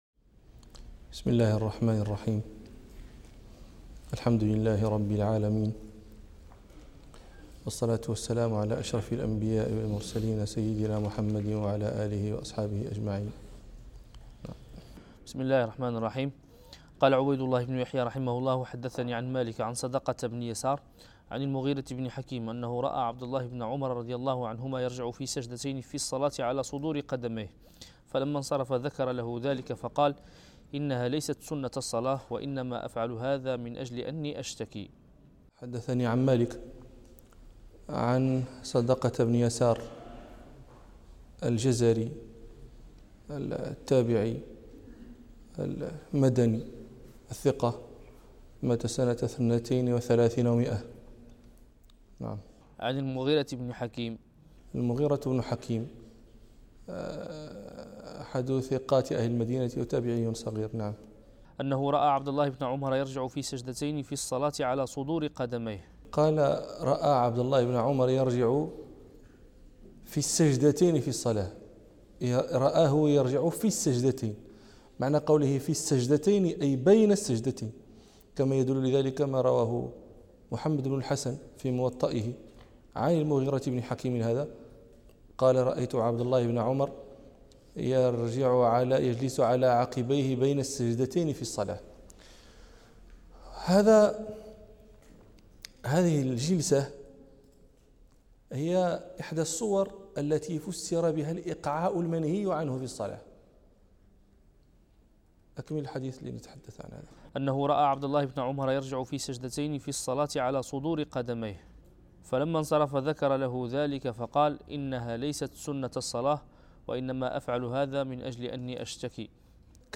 الدرس الخامس والسبعون من دروس كرسي الإمام مالك